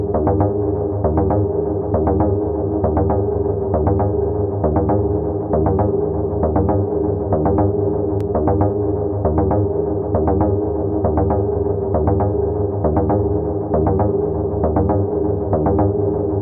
• techno pop background and airy chords.wav
techno_pop_background_and_airy_chords_6Ge.wav